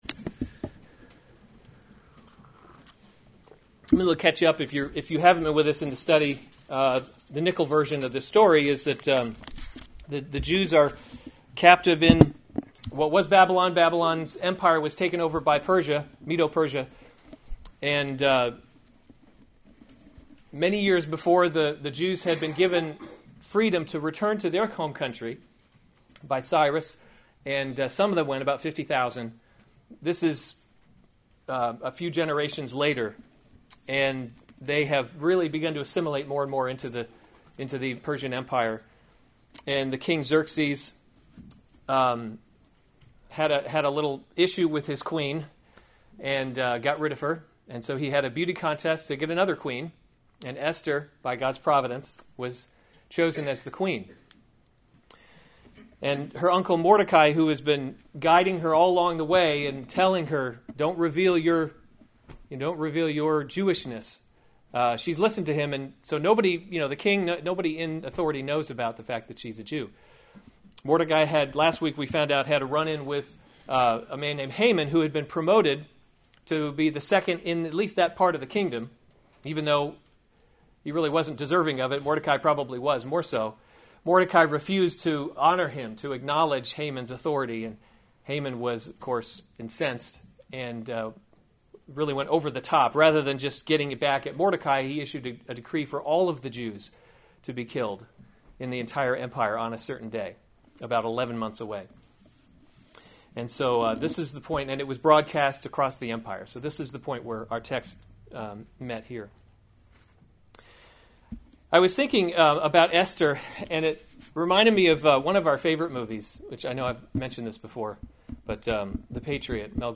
October 22, 2016 Esther: God’s Invisible Hand series Weekly Sunday Service Save/Download this sermon Esther 4:1-17 Other sermons from Esther Esther Agrees to Help the Jews 4:1 When Mordecai learned all […]